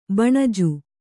♪ baṇaju